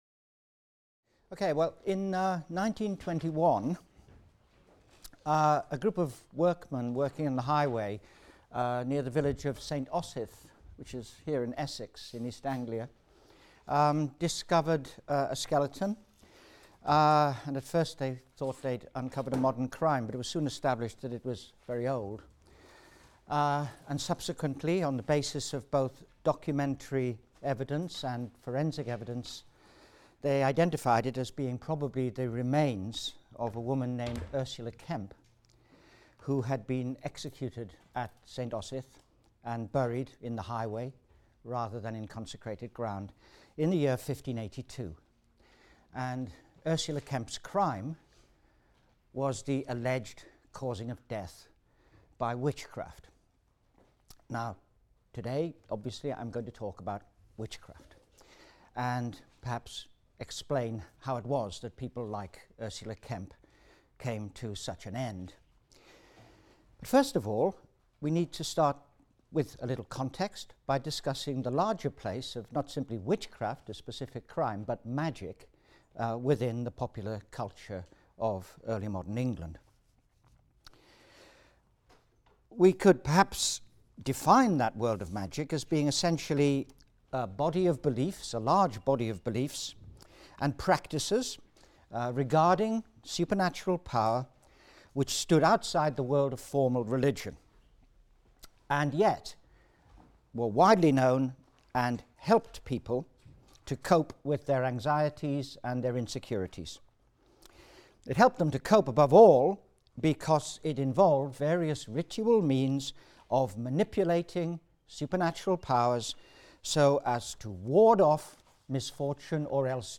HIST 251 - Lecture 14 - Witchcraft and Magic | Open Yale Courses